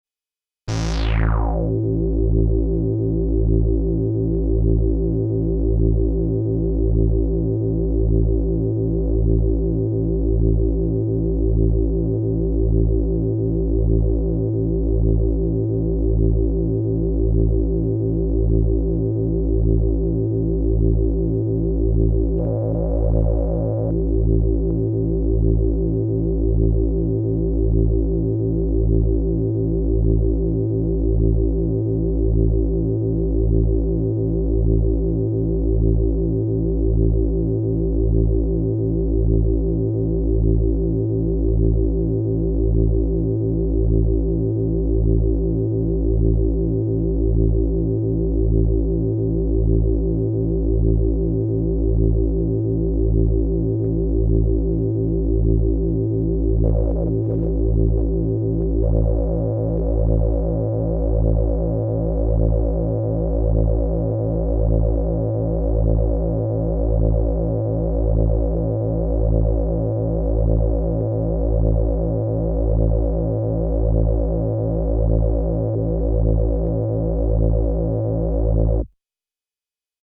VCF_DRIFT_PROBLEM.mp3